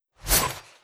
Melee_Jump_V2_2ch_01.wav